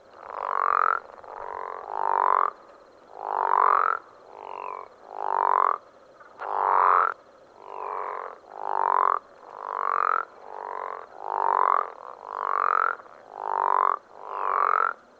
These frogs we calling from a flooded patch of prairie/pasture close to the Attwater's Prairie Chicken National Wildlife Refuge in Colorado County, Texas.
The call of the Crawfish Frog is loud and and easily heard over short distances.
The call is usually described as a "snore" which is a fairly apt description.
Crawfish Frogs - Colorado County, Texas
Interestingly, when you look at the spectrograph of their calls, you see that there is a descending higher pitched part to the call as well as the upward slurred snore.
You normally have trouble hearing the downward "whistle" over the loud snore, but I have edited out a selection of three calls here where the individual frog was further away and the whistle stands out more.
Staying with the "snoring" motif, the whistle sounds like the exaggerated whistled exhale that some snorers produce between snores, although in this case it is simultaneous with the snore.